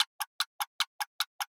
TickTock 05.wav